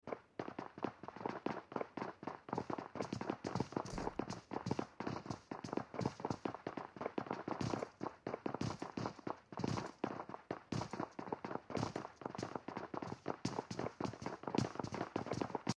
PUBG MOBILE enemy footsteps.